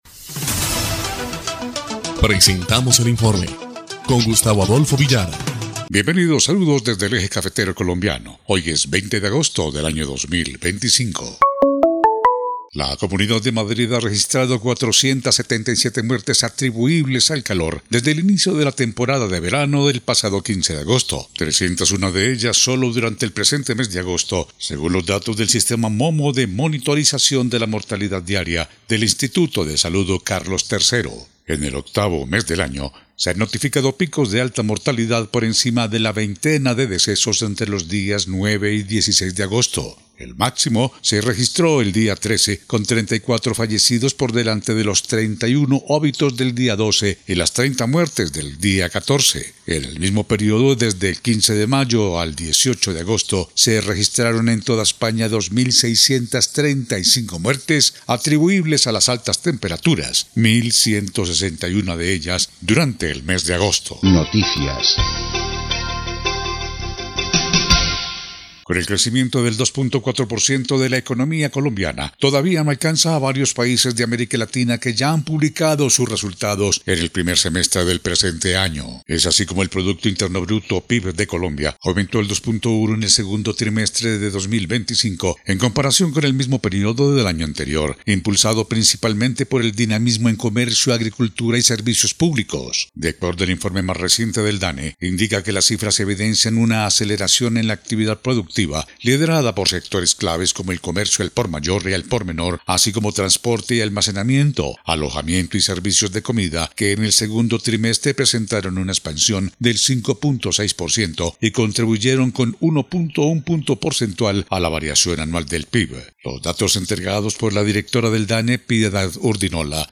EL INFORME 2° Clip de Noticias del 20 de agosto de 2025